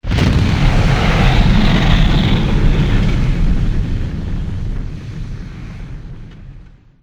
Fire2.wav